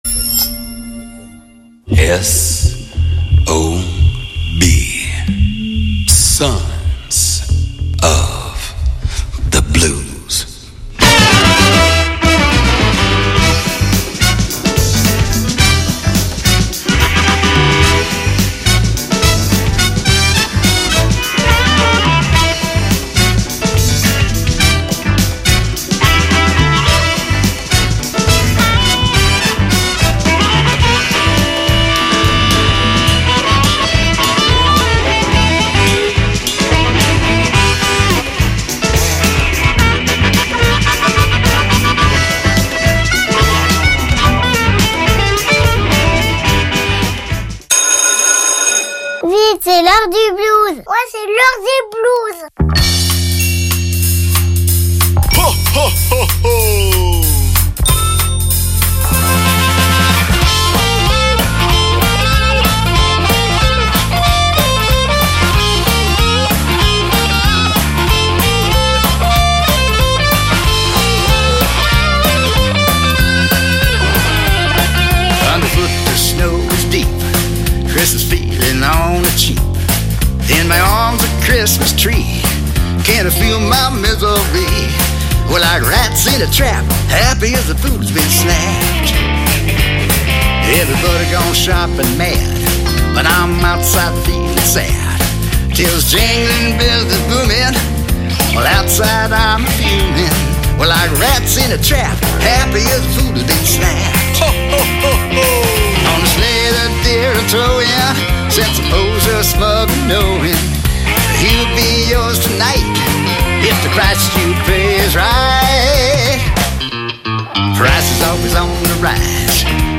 Sons of Blues : musiques blues